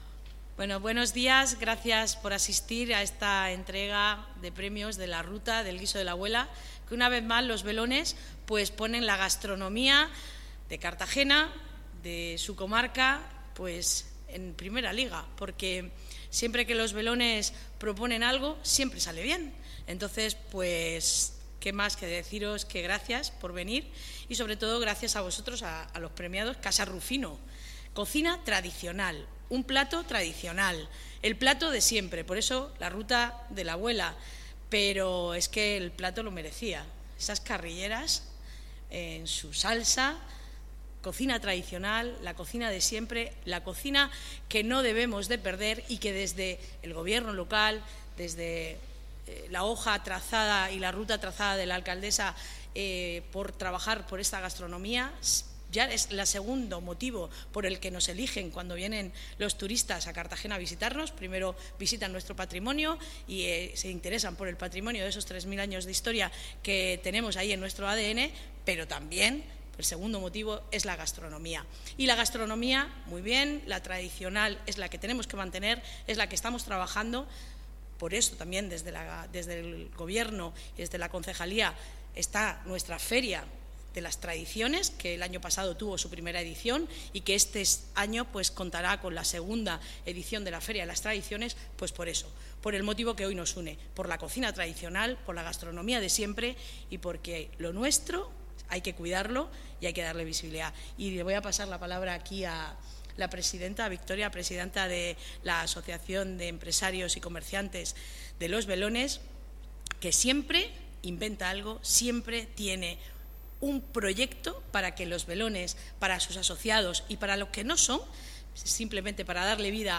La XI Ruta del guiso de la abuela de Los Belones ya tiene ganador, el Restaurante Casa Rufino ha sido el más votado con sus carrilladas en salsa, cocina tradicional que siempre es muy bien acogida, tal y como ha señalado la concejal de Comercio, Belén Romero, durante la entrega del premio que ha tenido lugar este lunes 16 de marzo en el Palacio Consistorial.
Entrega del premio al ganador de la XI Ruta del guiso de la abuela de Los Belones